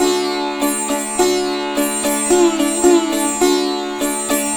105-SITAR3-L.wav